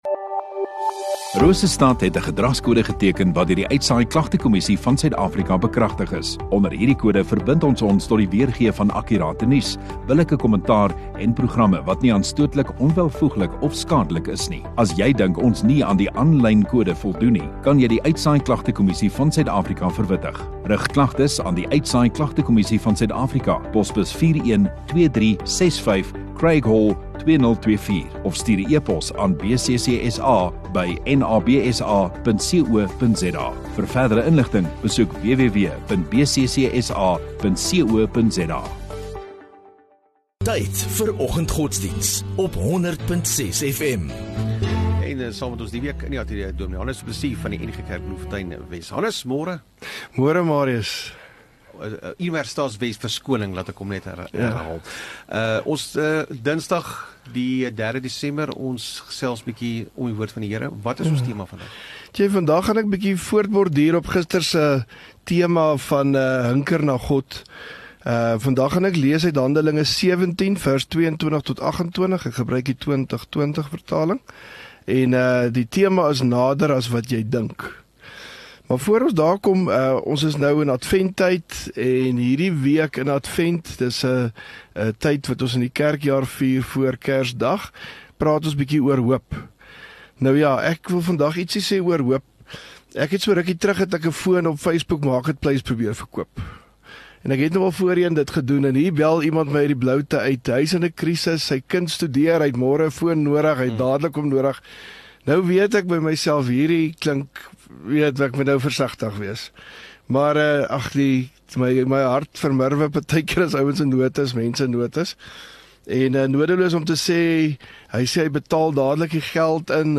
3 Dec Dinsdag Oggenddiens